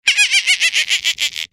دانلود صدای میمون 4 از ساعد نیوز با لینک مستقیم و کیفیت بالا
جلوه های صوتی